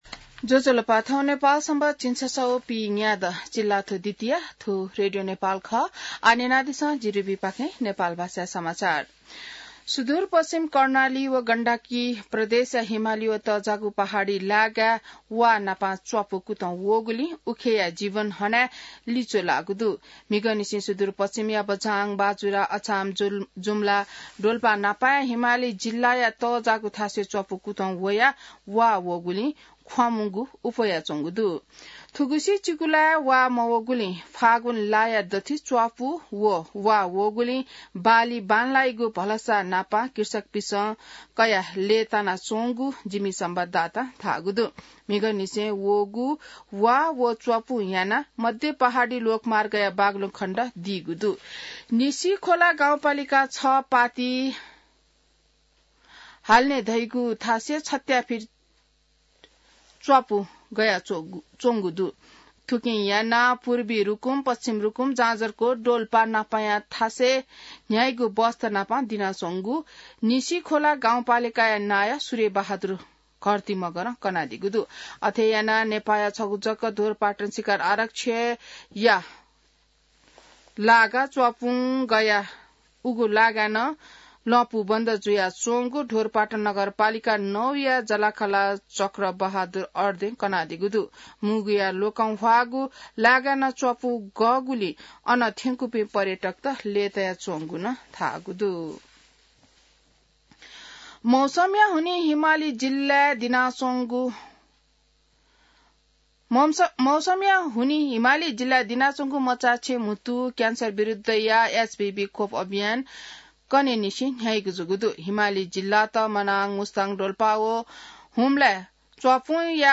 नेपाल भाषामा समाचार : १८ फागुन , २०८१